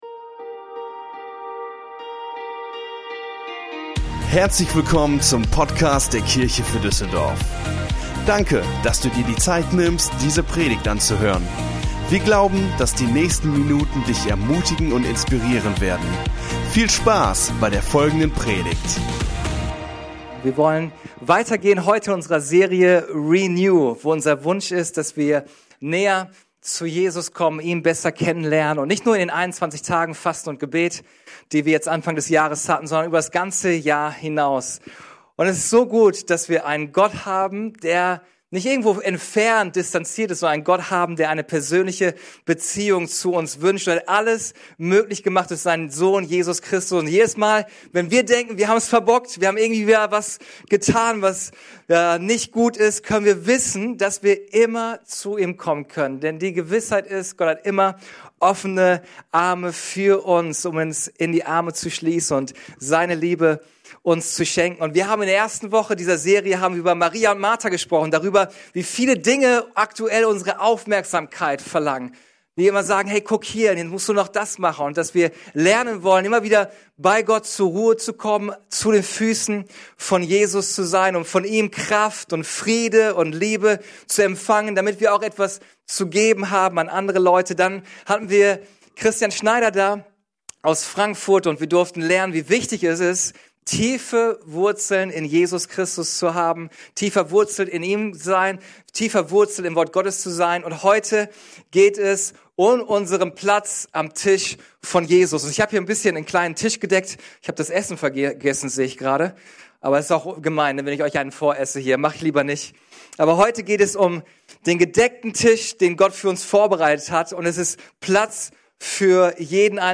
Der dritte Teil unserer Predigtserie: "renew" Folge direkt herunterladen